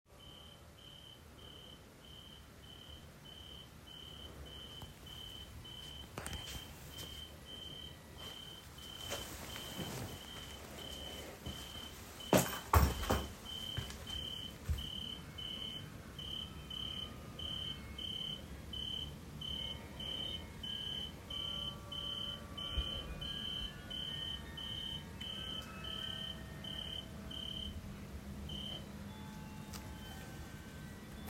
Een mediterraan zomergeluid in een Zutphens tuintje gisteravond tegen zeven uur. Een krekel, dat was duidelijk.
Luider was het sowieso.
Nederlandse naam: boomkrekel.
Op de opname wordt het muzikale insect begeleid door het carillon in de Wijnhuistoren.
Boomkrekel-en-carillon.mp3